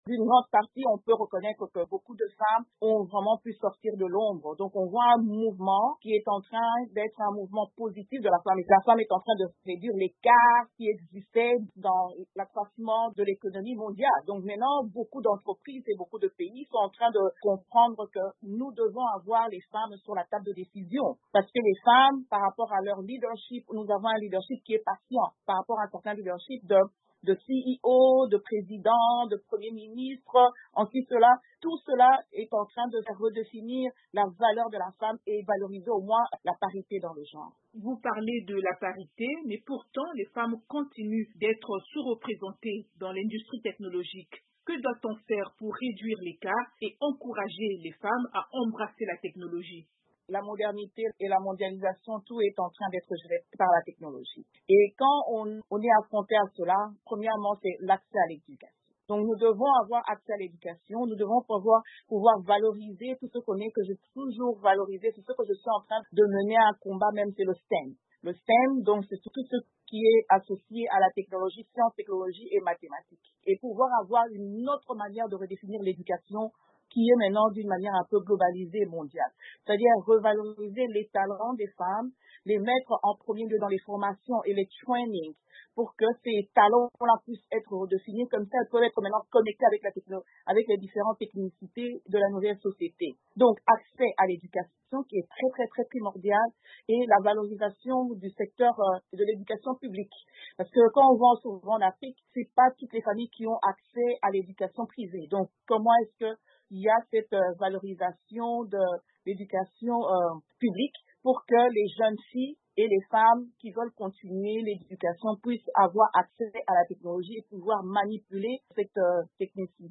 Femmes et technologie: entretien avec Micky Bondo, élue américaine